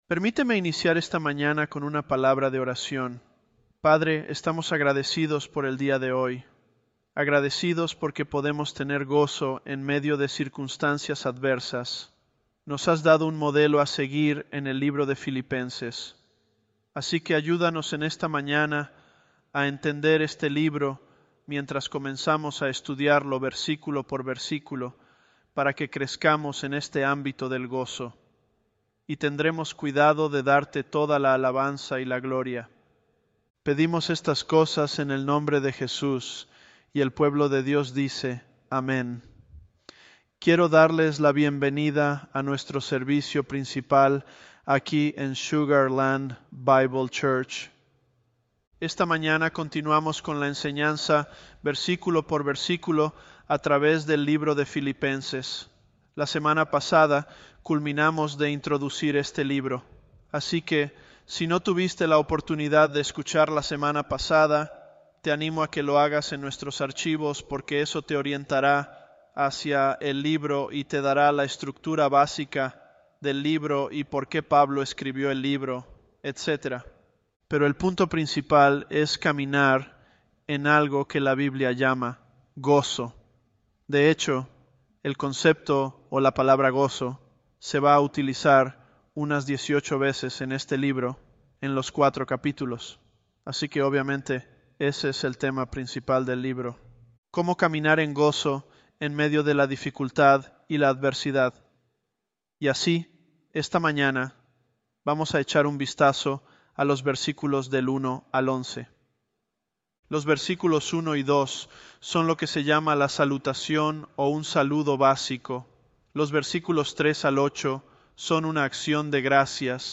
Sermons
Elevenlabs_Philippians002.mp3